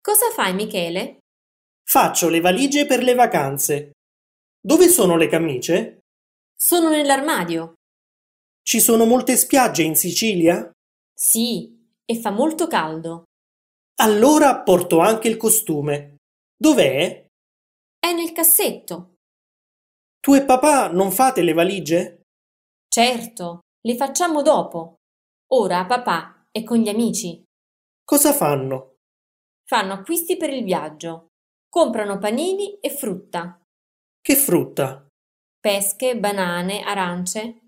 B1-Facciamo-le-valigie-Dialogo.mp3